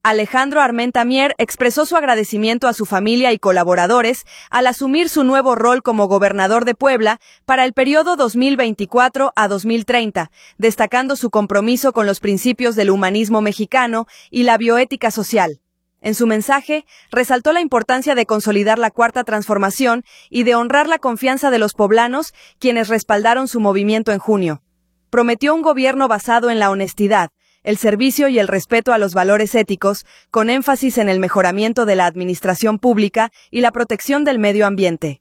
Alejandro Armenta Mier expresó su agradecimiento a su familia y colaboradores al asumir su nuevo rol como gobernador de Puebla para el periodo 2024-2030, destacando su compromiso con los principios del Humanismo Mexicano y la Bioética Social.